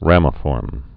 (rămə-fôrm)